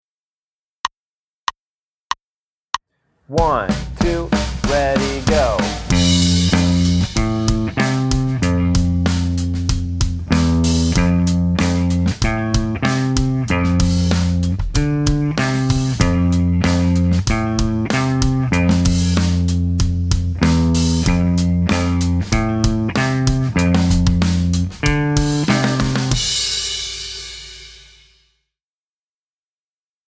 Voicing: Guitar w/C